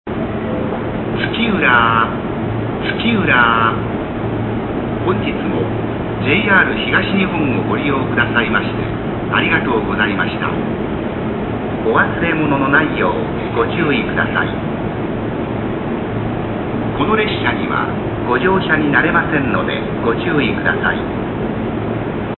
今までとは大幅に異なり、特に男声の駅名連呼や次発放送などがその一つ
駅名連呼終着終着時の駅名連呼です。今までと言い回しがかなり異なります。